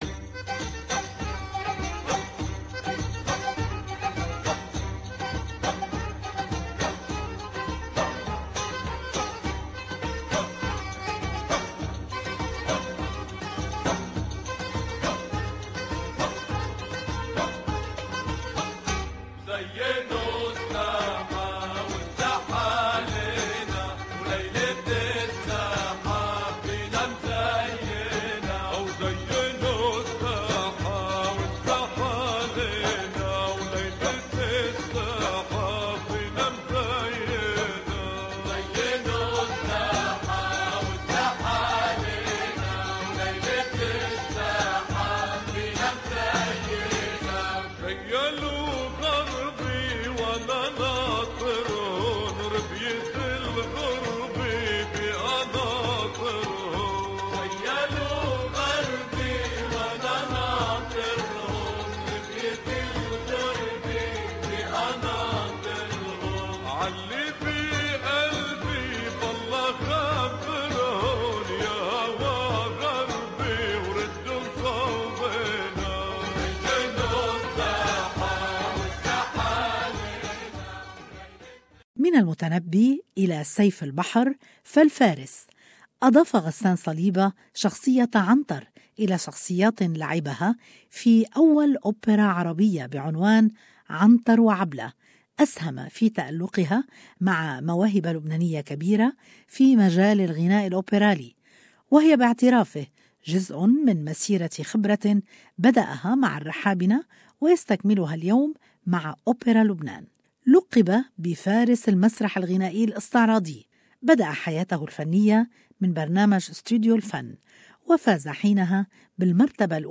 Ghassan Saliba is a Lebanese singer well known for his patriotic character, either on stage - through the musical plays - or through his songs, and for his strong and warm voice. Ghassan Saliba has undoubtedly one of the widest voices among the new generation singers not only in Lebanon but in the whole Arab world.More in this interview where he talks about his performance in the annual MOME dinner on Saturday May 20.